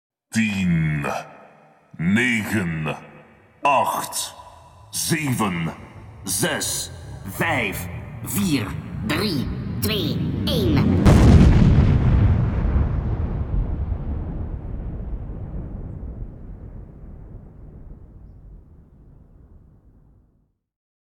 Kermis geluid Aftellen Nederlands
Categorie: Geluidseffecten
Dit geluid, rechtstreeks uit de Kermis Geluiden Database, is perfect voor het creëren van sfeer en anticipatie.
kermis geluiden, geluidseffecten
kermis-geluid-aftellen-nederlands-nl-www_tiengdong_com.mp3